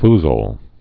(fzəl)